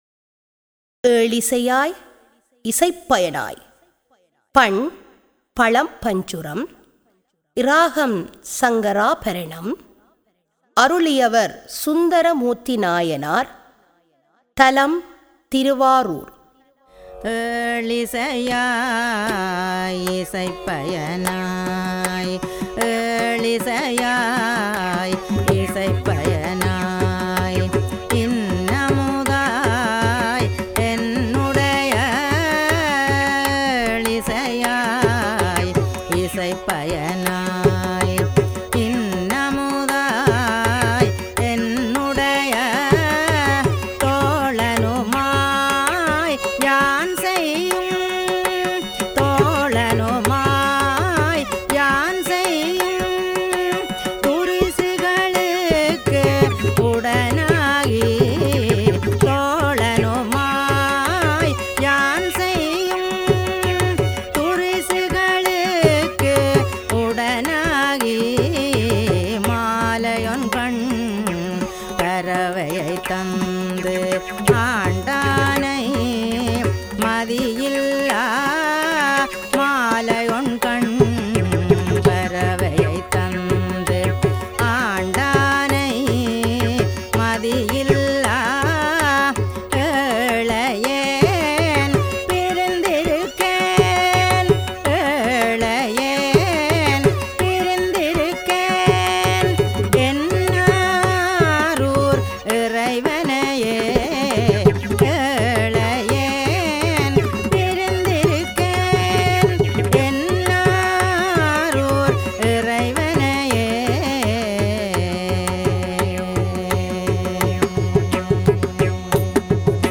தரம் 9 இல் கல்வி பயிலும் சைவநெறிப் பாடத்தை கற்கும் மணவர்களின் நன்மை கருதி அவர்கள் தேவாரங்களை இலகுவாக மனனம் செய்யும் நோக்கில் இசைவடிவக்கம் செய்யப்பட்ட தேவாரப்பாடல்கள் இங்கே பதிவிடபட்டுள்ளன.